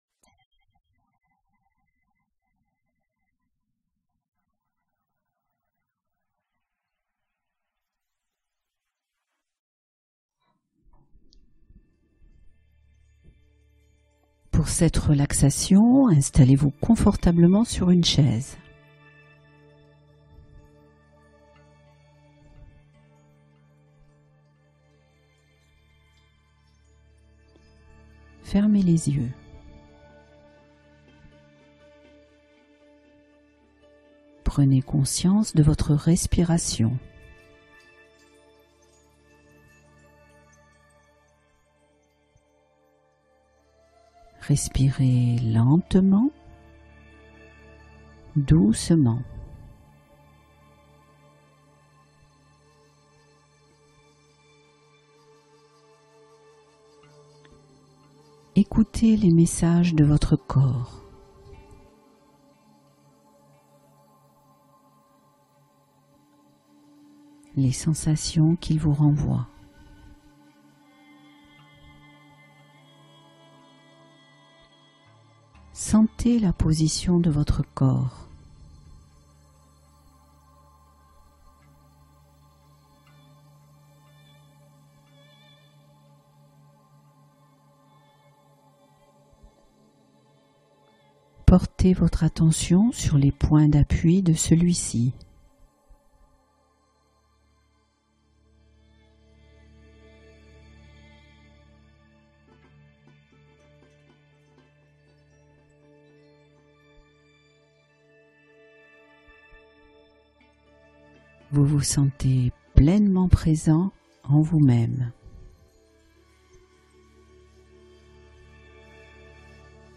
Relaxation guidée : apaiser la puissance des migraines